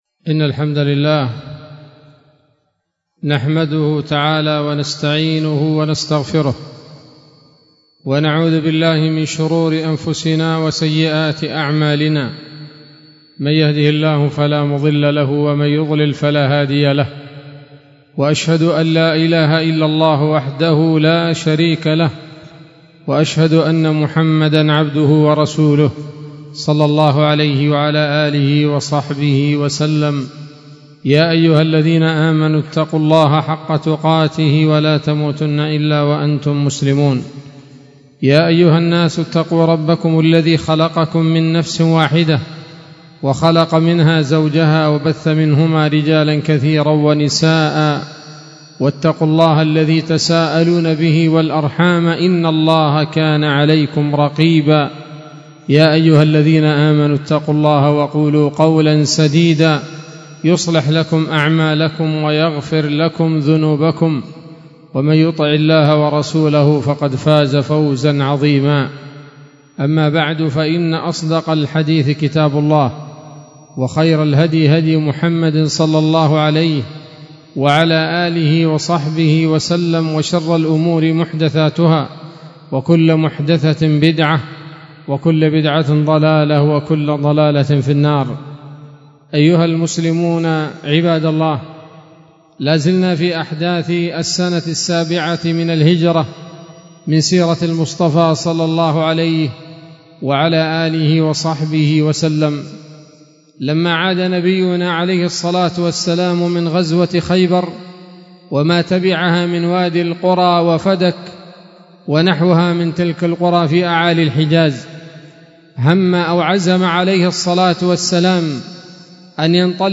خطبة جمعة بعنوان: (( السيرة النبوية [27] )) 20 محرم 1446 هـ، دار الحديث السلفية بصلاح الدين